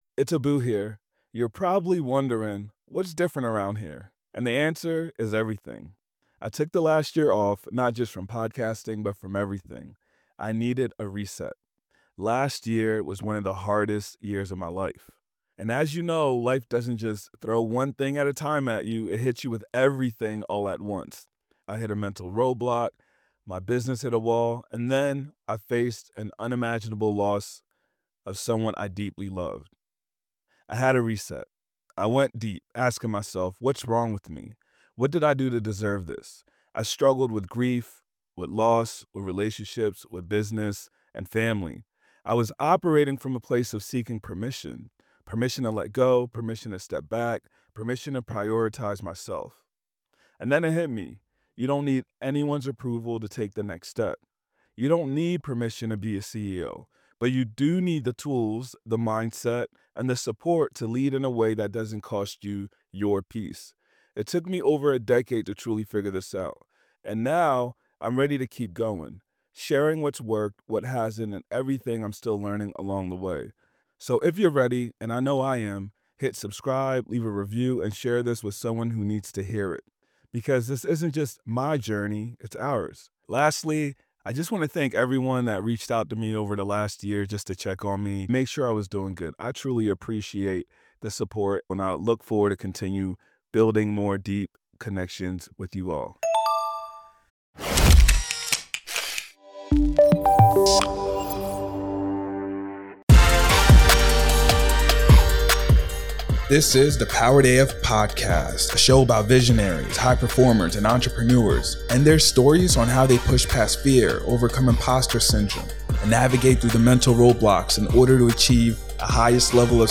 This was an amazing conversation with an amazing visionary.